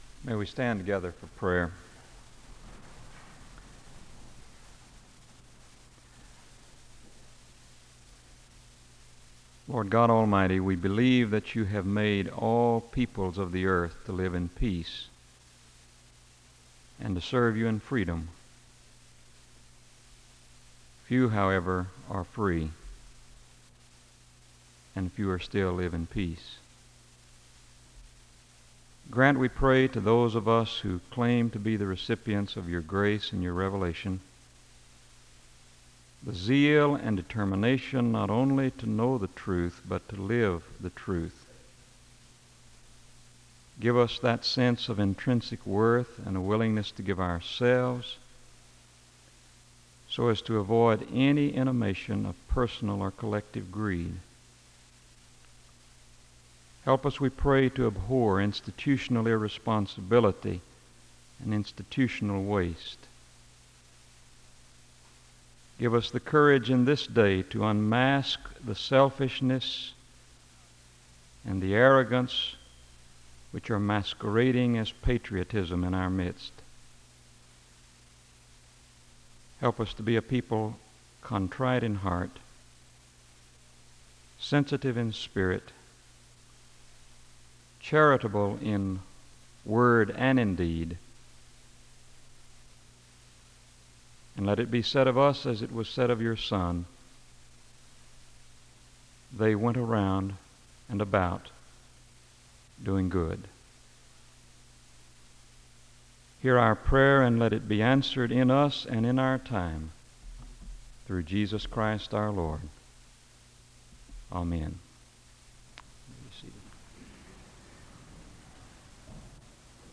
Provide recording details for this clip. The service begins with a word of prayer (0:00:00-0:01:55). The service ends with the speaker dismissing the audience (1:00:03-1:00:34). SEBTS Chapel and Special Event Recordings SEBTS Chapel and Special Event Recordings